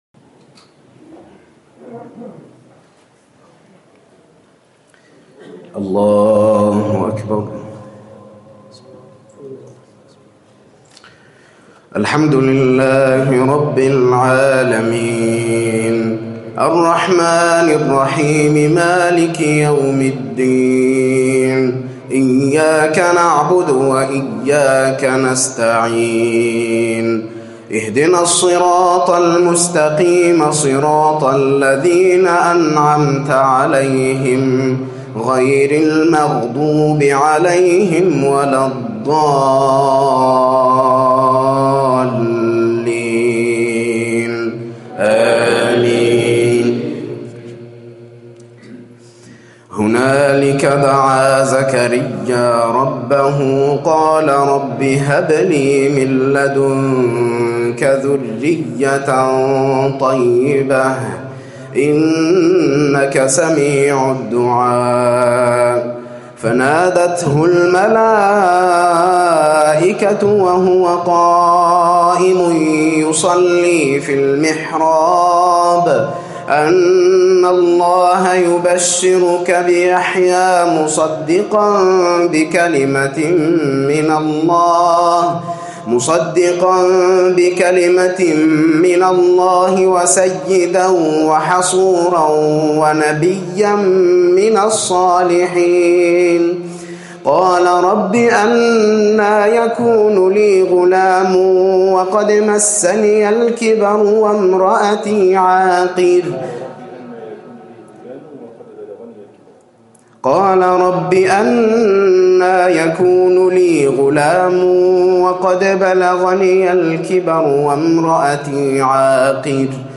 قرأ من سورة آل عمران من الأيه 38 الى 54